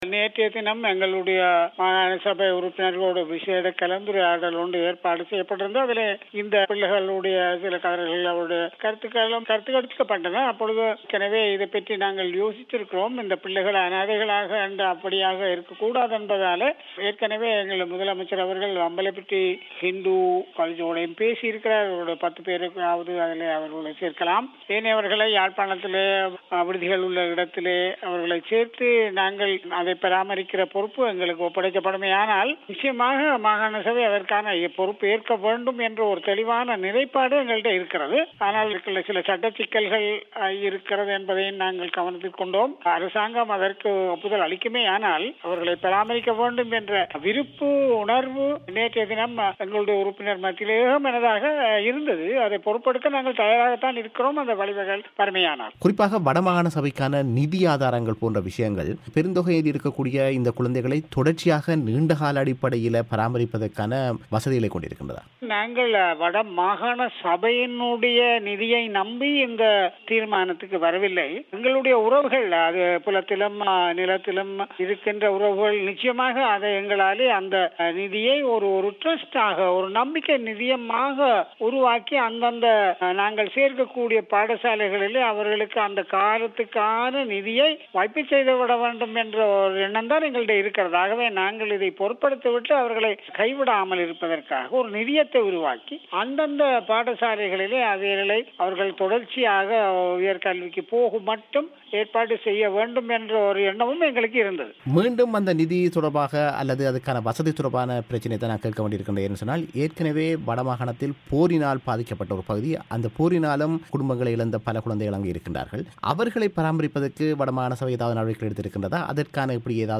இந்த விடயம் குறித்த அவர் பிபிசிக்கு வழங்கிய செவ்வியை நேயர்கள் இங்கு கேட்கலாம்.